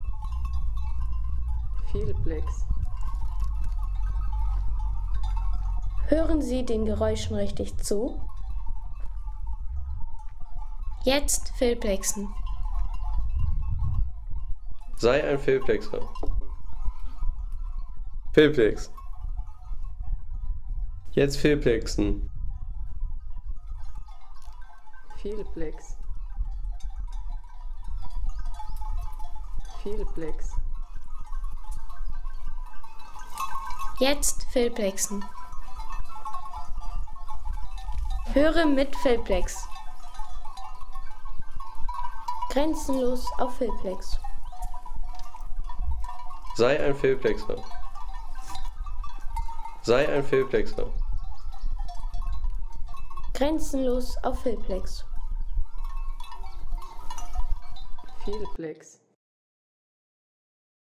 Bergziegen Glöckchen
Bergziegen Glöckchen Home Sounds Tierwelt Bauernhof-Tiere Bergziegen Glöckchen Seien Sie der Erste, der dieses Produkt bewertet Artikelnummer: 3 Kategorien: Tierwelt - Bauernhof-Tiere Bergziegen Glöckchen Lade Sound....